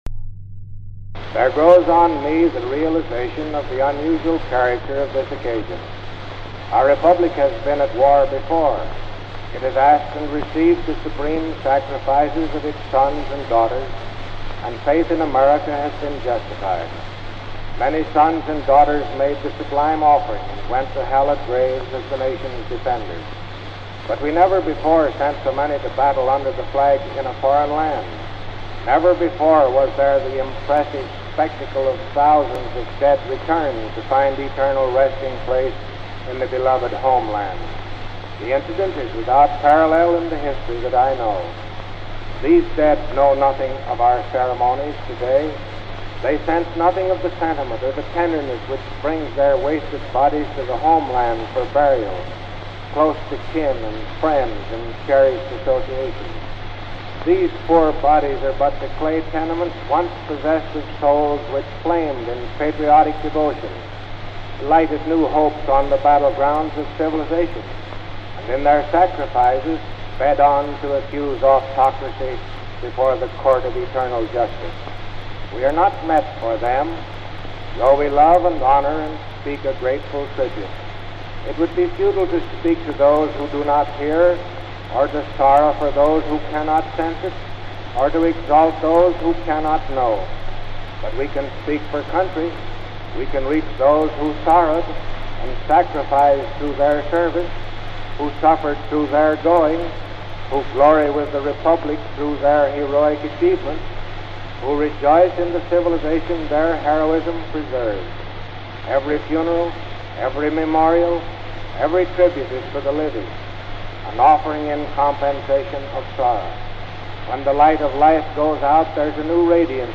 May 23, 1921: Speech Upon Arrival of World War One Dead for Burial | Miller Center
Presidential Speeches | Warren G. Harding Presidency